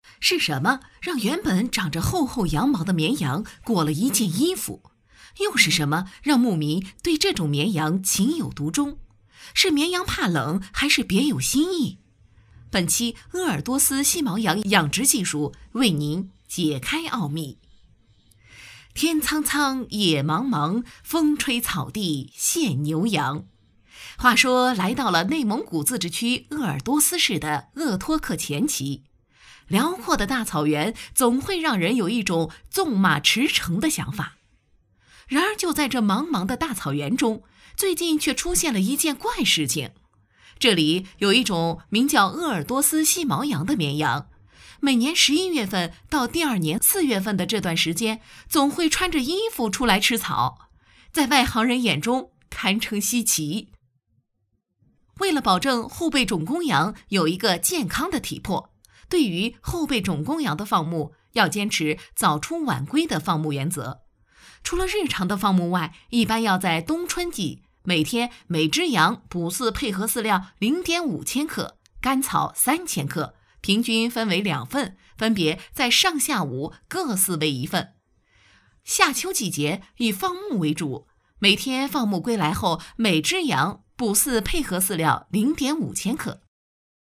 纪录片配音作品在线试听-优音配音网
女声配音
纪录片女国104